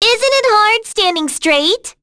Rehartna-Vox_Skill2_b.wav